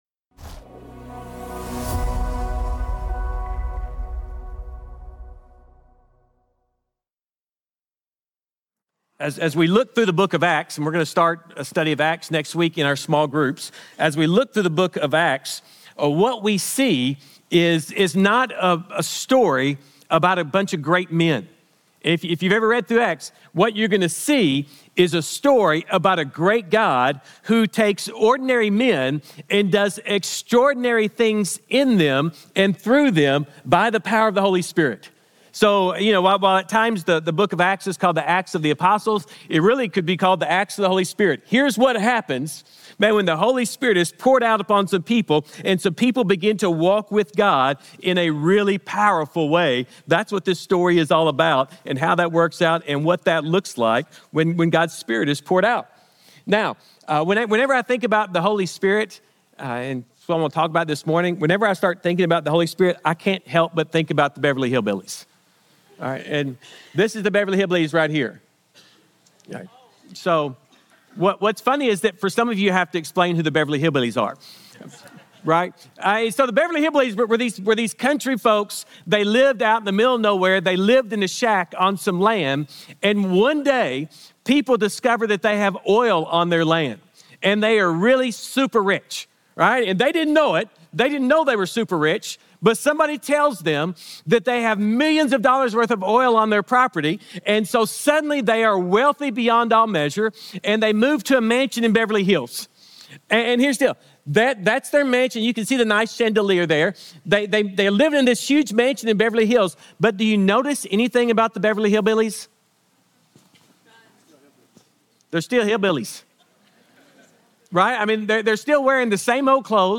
Sermons | Grace Community Church
01-12-25 Sunday Morning